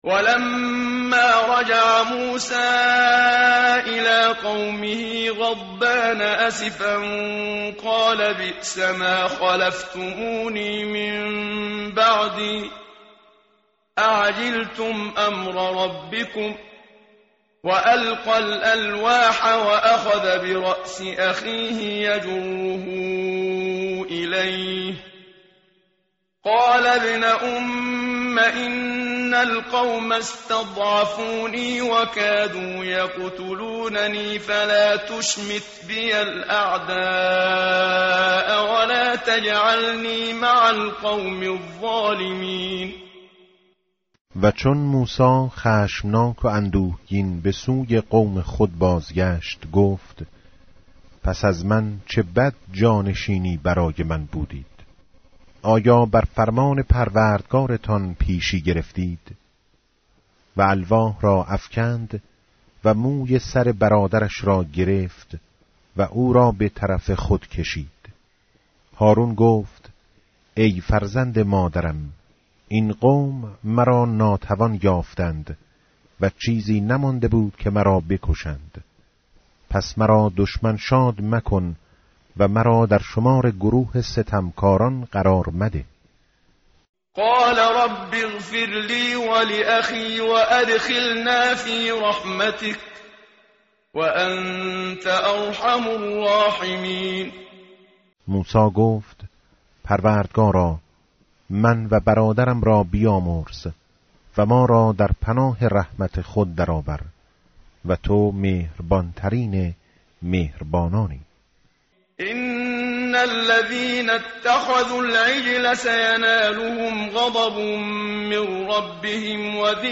متن قرآن همراه باتلاوت قرآن و ترجمه
tartil_menshavi va tarjome_Page_169.mp3